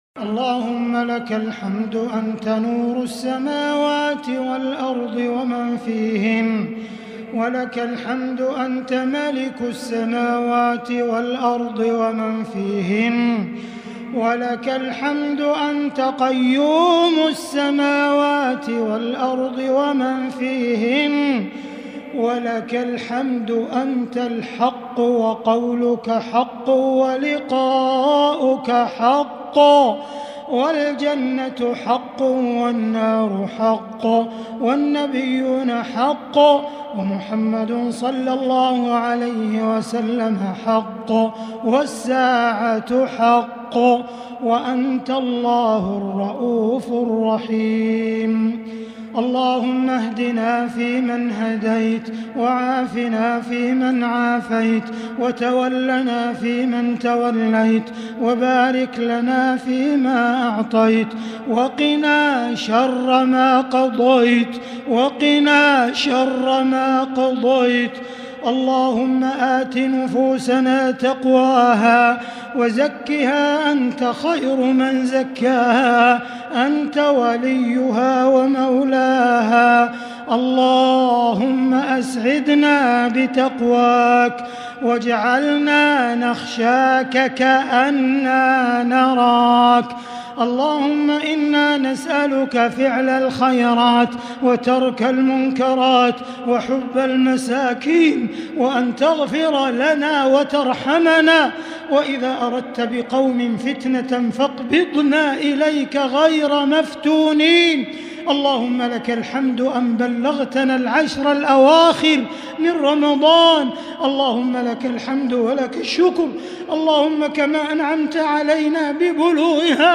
دعاء القنوت ليلة 21 رمضان 1441هـ > تراويح الحرم المكي عام 1441 🕋 > التراويح - تلاوات الحرمين